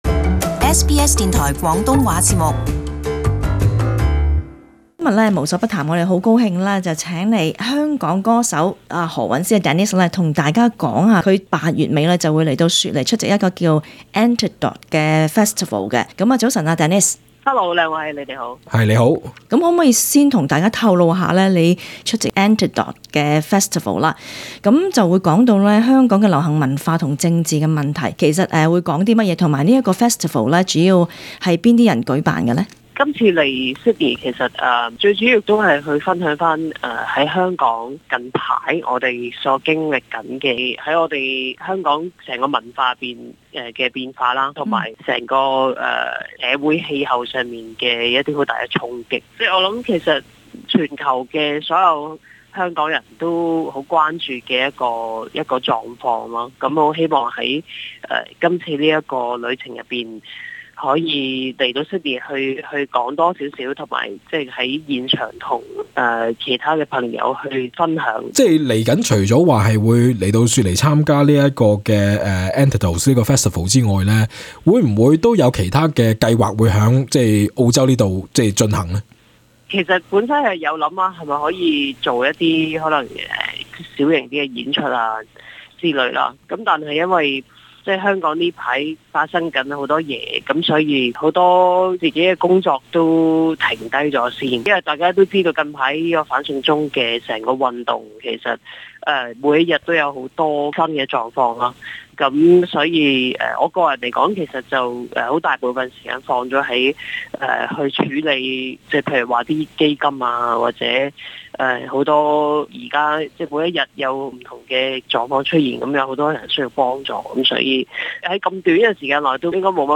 【無所不談】不只歌手咁簡單－ 訪何韻詩